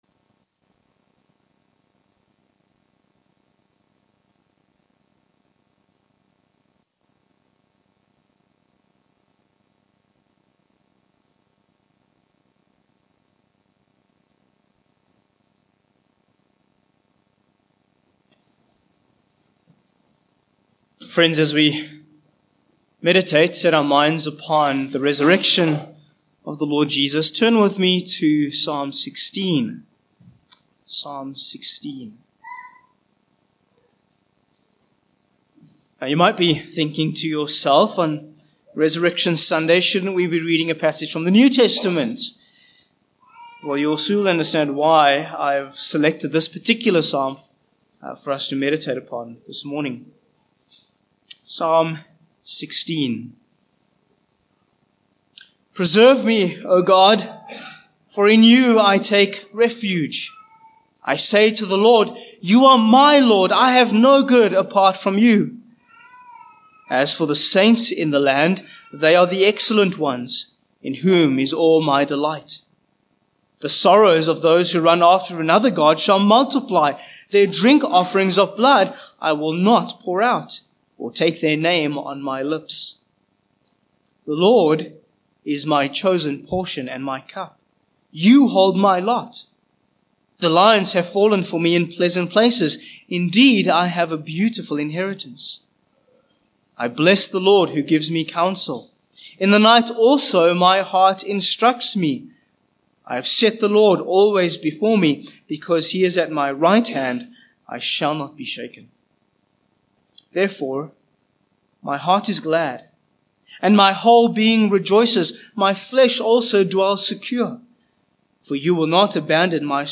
Series: Easter Services
Psalm 16:1-11 Service Type: Morning Passage